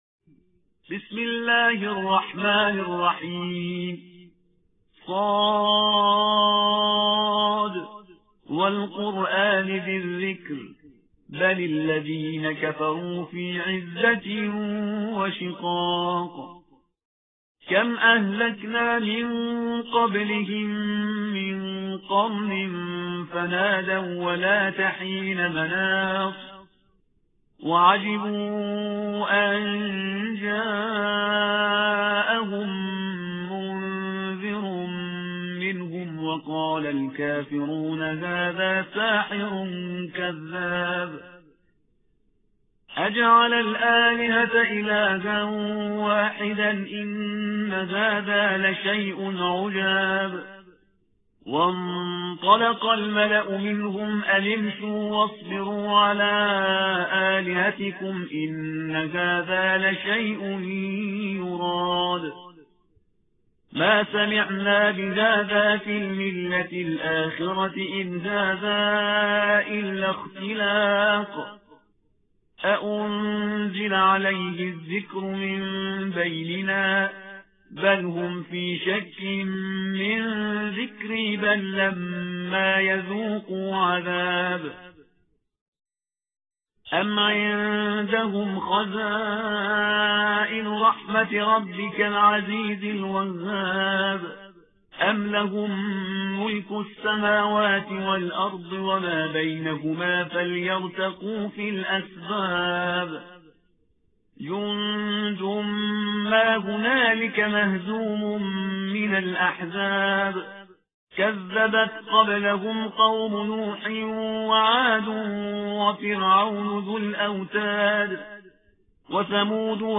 صوت قاری و عکس تمارین ارسال می شود.